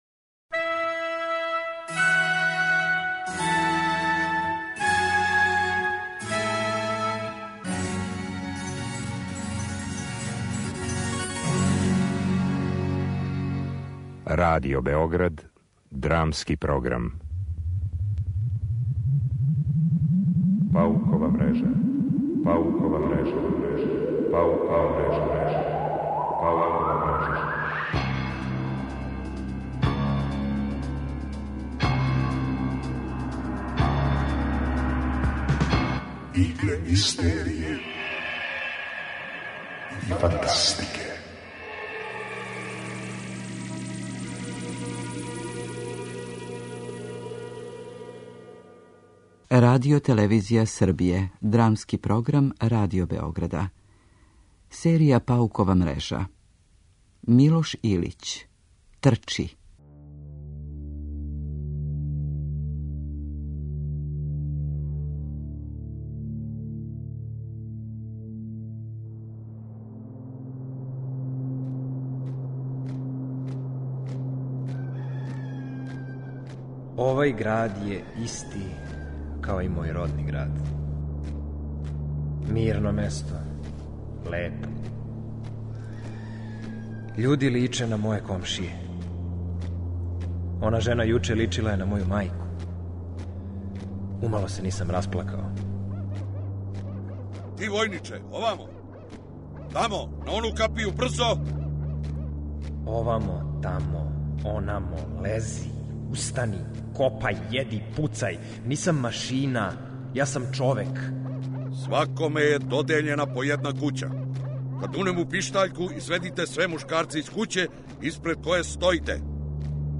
DRAMA UTORAK.mp3